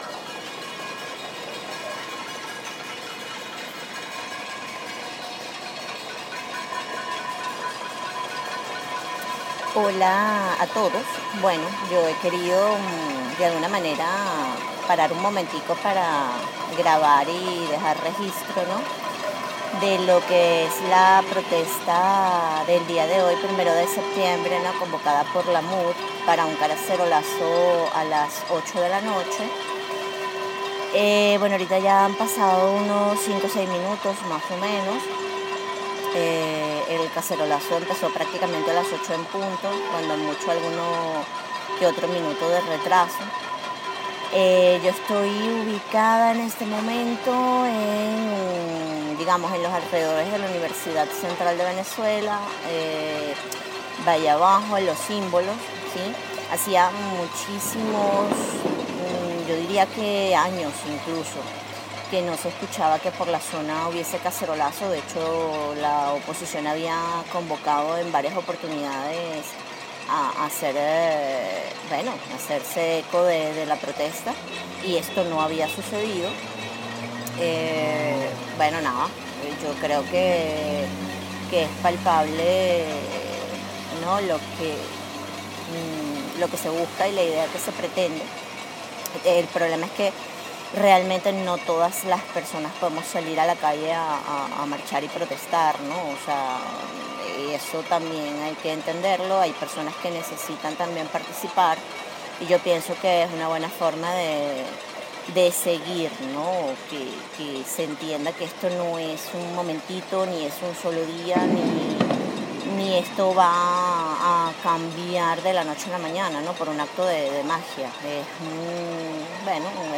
protesta con cacerolas 1 de septiembre de 2016 8:00 de la noche en Caracas Venezuela